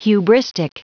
Prononciation du mot : hubristic